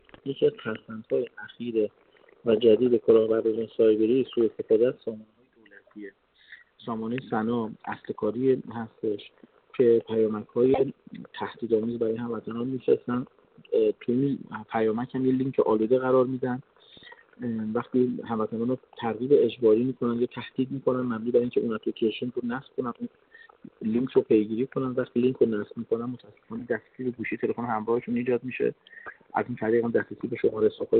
در همین راستا سرهنگ رامین پاشایی، معاون فرهنگی و اجتماعی پلیس فتا در گفت‌وگو با ایکنا نسبت به کلاهبرداری از شهروندان در پوشش پیامک جعلی سامانه ثنا هشدار داد و اظهار کرد: متأسفانه شاهد هستیم که کلاهبرداران سایبری از سامانه‌های دولتی مانند سامانه ثنا سوءاستفاده می‌کنند و برای برخی از شهروندان مزاحمت ایجاد کرده‌اند.